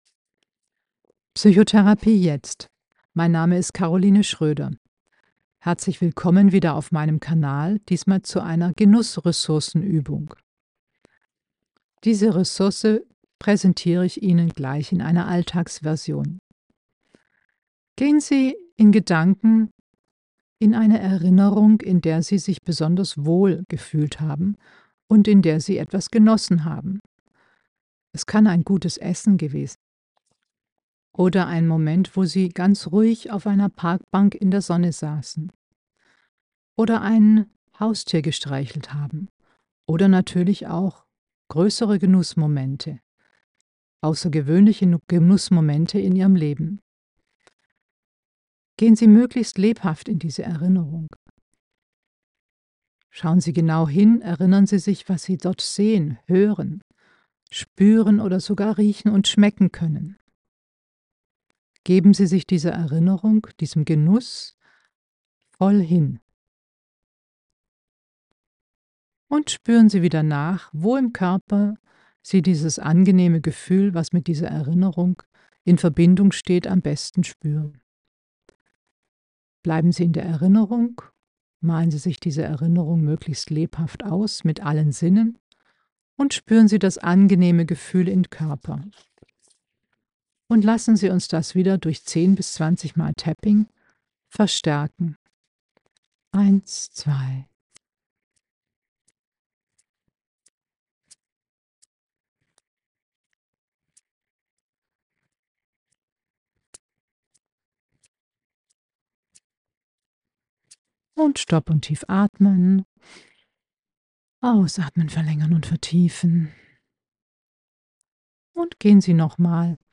Stabilisierungsübung mit BLS aus der EMDR Eye Movement Desensitization and Reprocessing Therapie mit dem Inhalt einer genußreichen Erfahrung ...